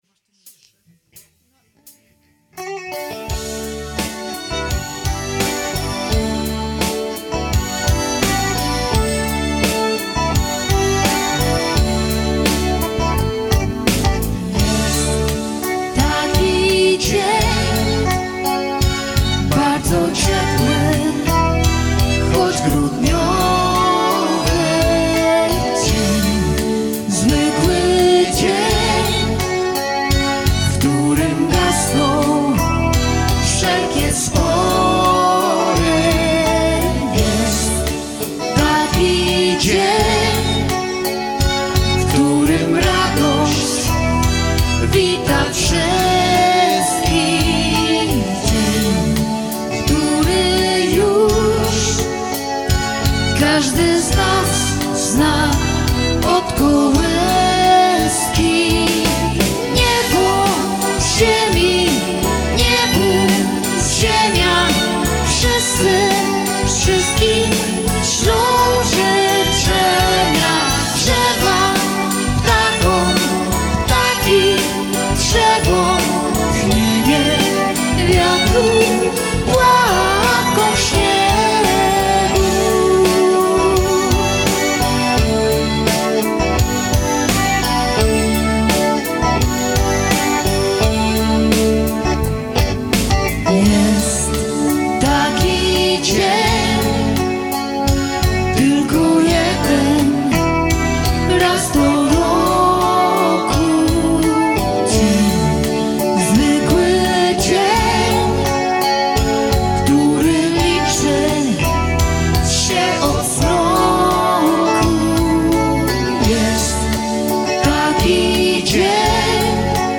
Jasełka Bożonarodzeniowe
Kolęda